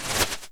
Wings2.wav